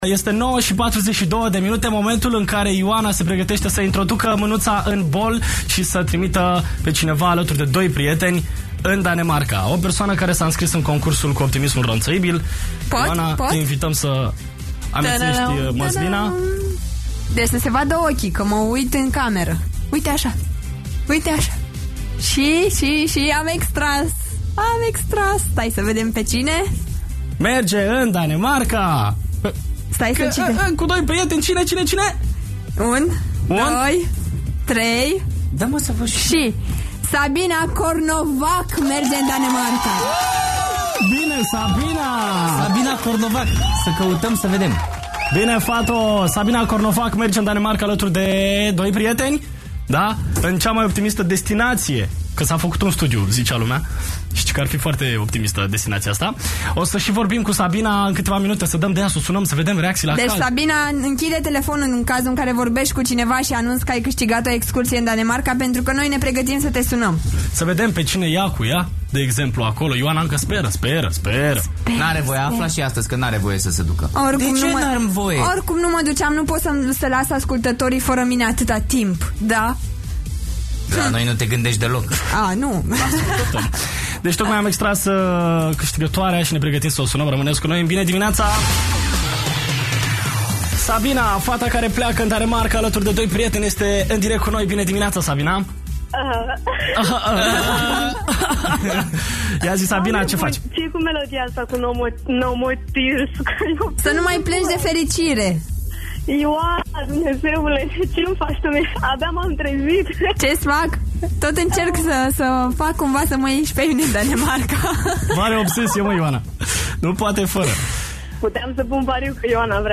Asa am aflat eu de dimineata ca am castigat excursia in Danemarca, oferita de Radio 21 si Pufuletii Gusto. 🙂